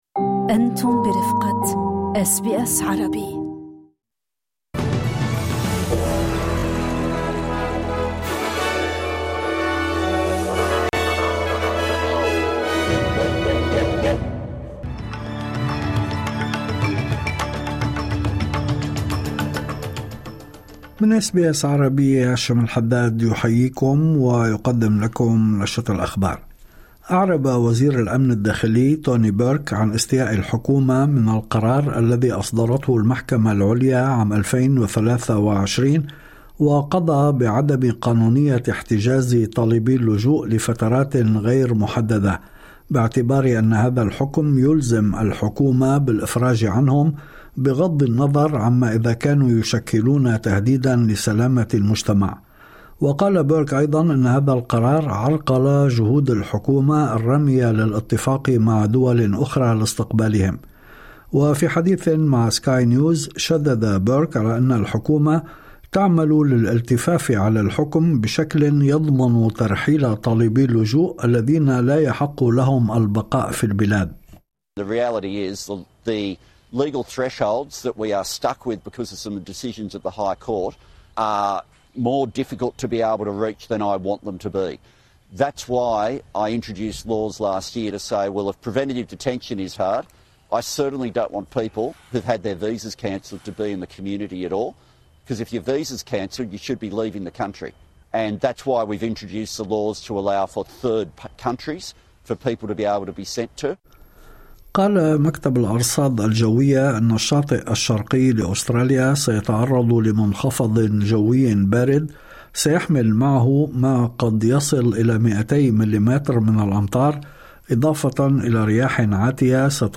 نشرة أخبار الظهيرة 30/6/2025